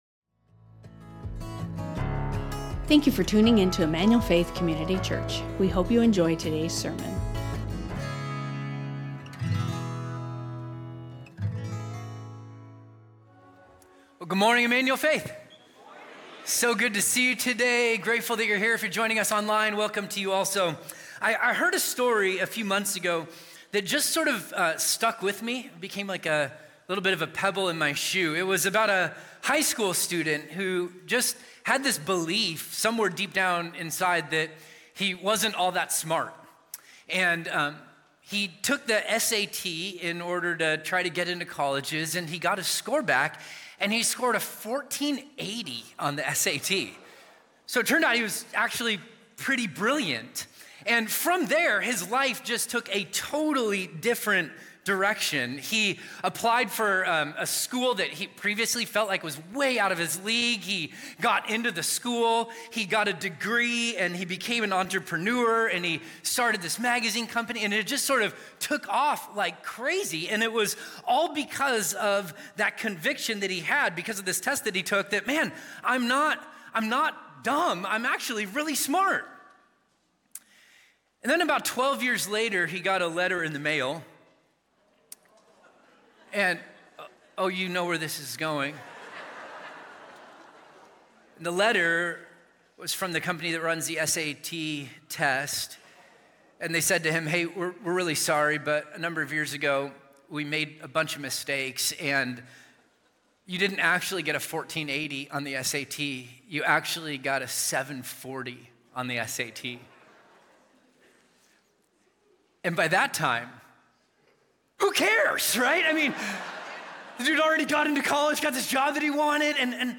Emmanuel Faith Sermon Podcast Identity | 1 Peter 2:9-10 Feb 02 2026 | 00:46:52 1x 00:00 / 00:46:52 Subscribe Share Spotify Amazon Music RSS Feed Share Link Embed